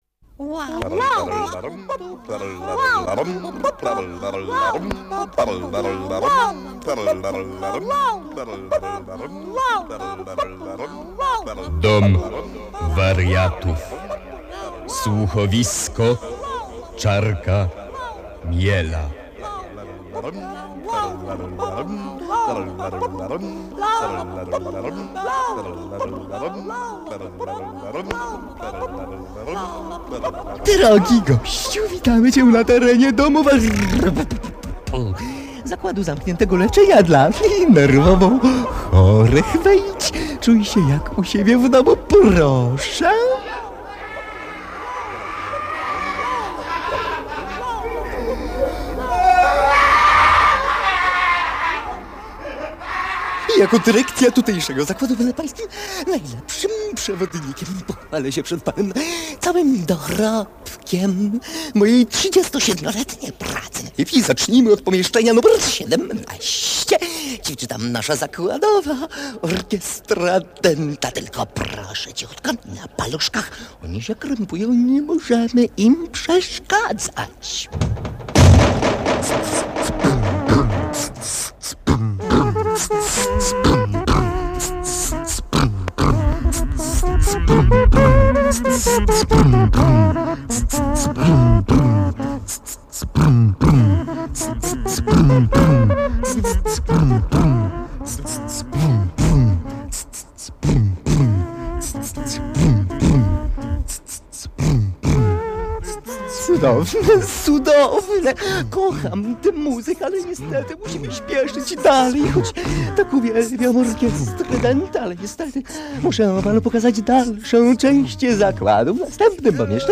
Monolog pacjenta szpitala psychiatrycznego